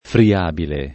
[ fri- # bile ]